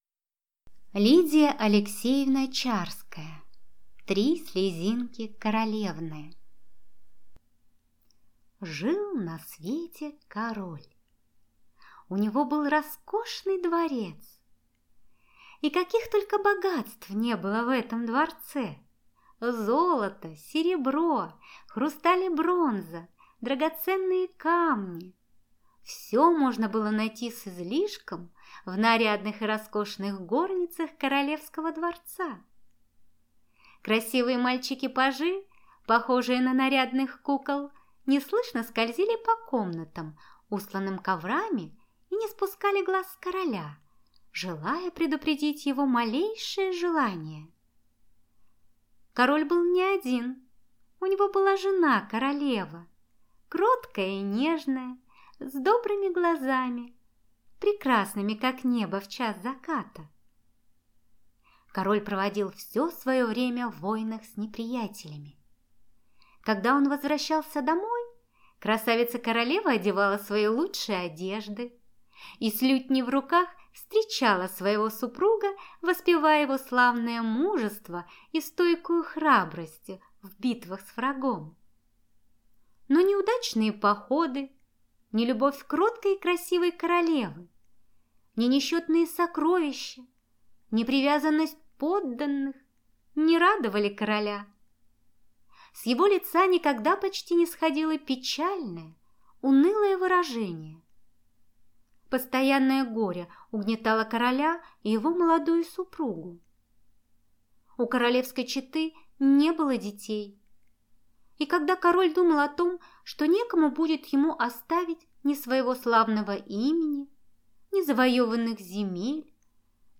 Аудиокнига Три слезинки королевны | Библиотека аудиокниг
Прослушать и бесплатно скачать фрагмент аудиокниги